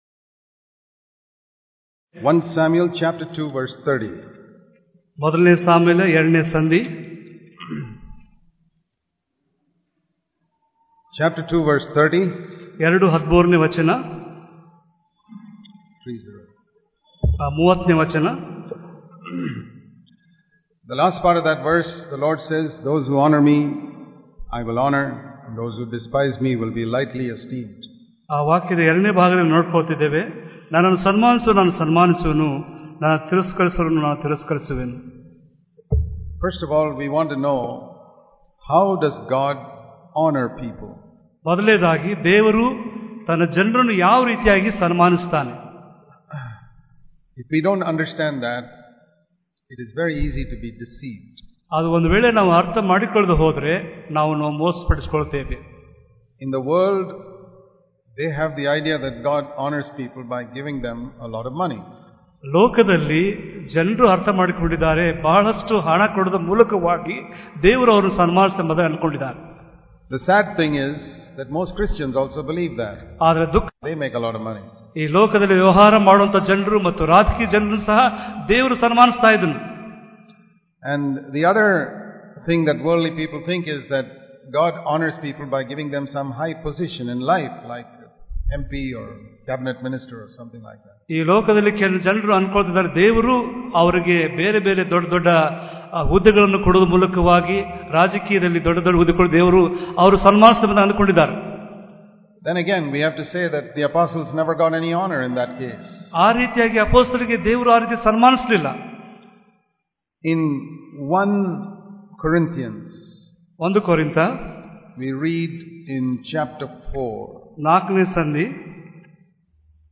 ಧರ್ಮೋಪದೇಶದ